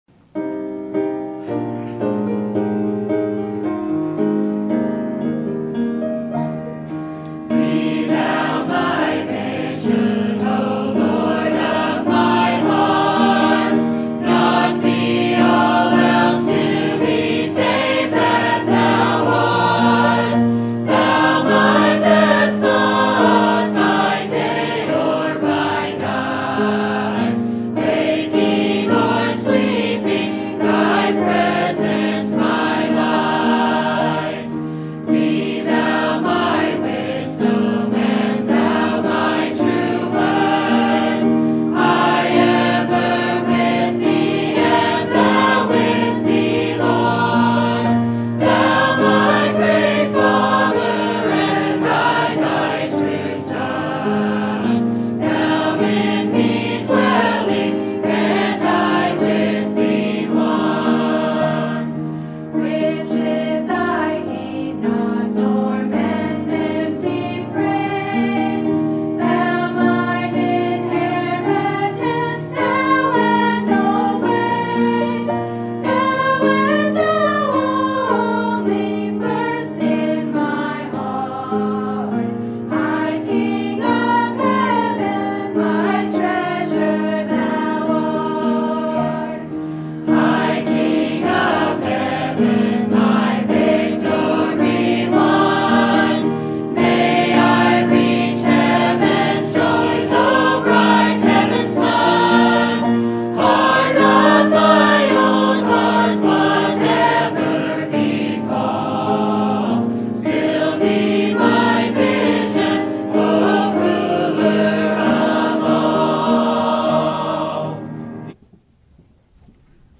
Sermon Topic: General Sermon Type: Service Sermon Audio: Sermon download: Download (24.15 MB) Sermon Tags: Luke Word Faithfulness Sin